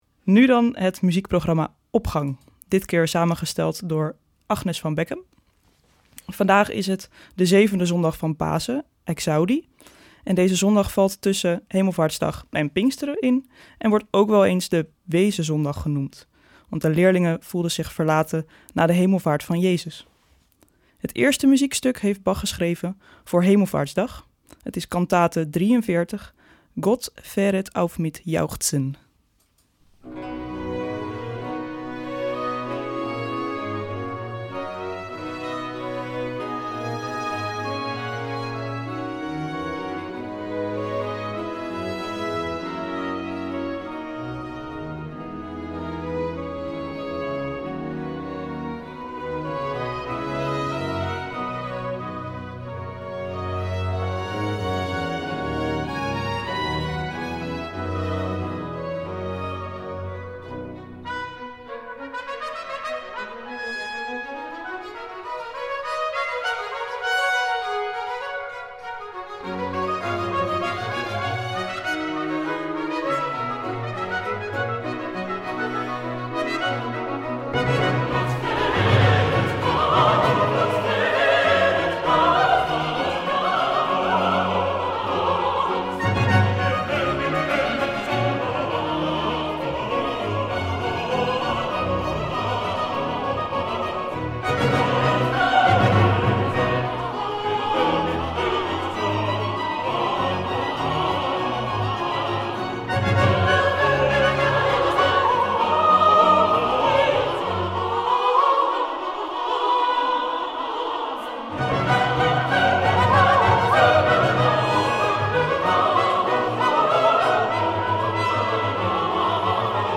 Opening van deze zondag met muziek, rechtstreeks vanuit onze studio.
Het weemoedige Cum Dederit van Vivaldi sluit aan bij de sfeer van deze Wezenzondag.